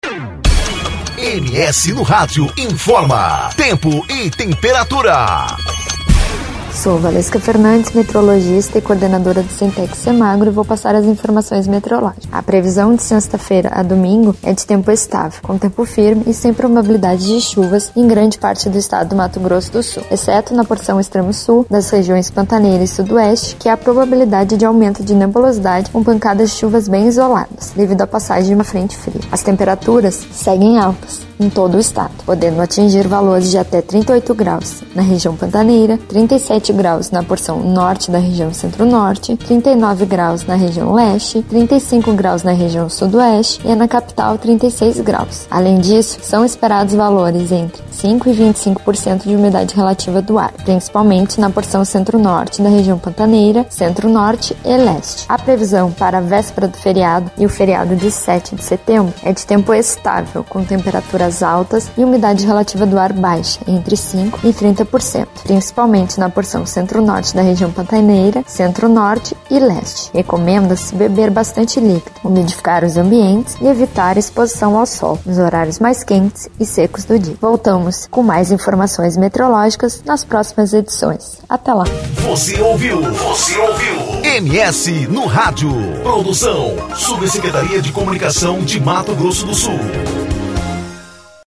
Previsão do Tempo: Frente fria pode trazer chuva a região pantaneira